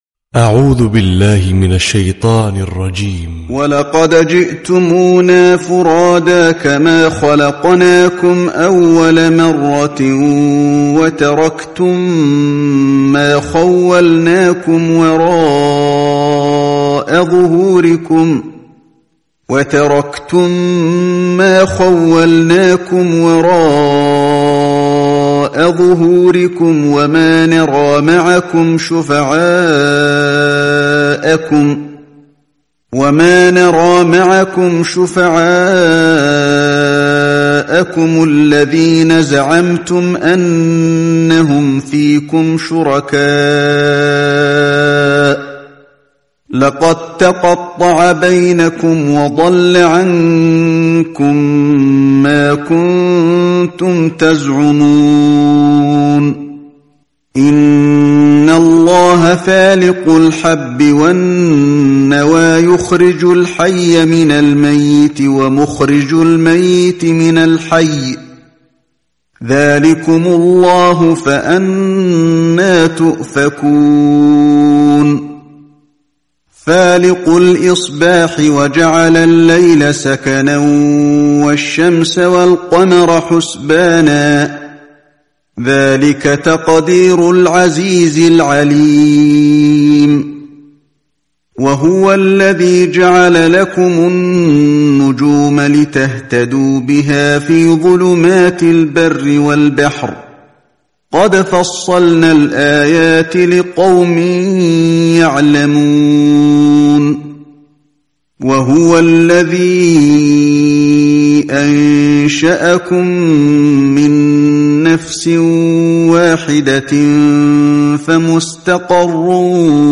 2. القرآن الكريم
• القارئ : القارئ الشيخ علي الحذيفي